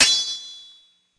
buildmetal.mp3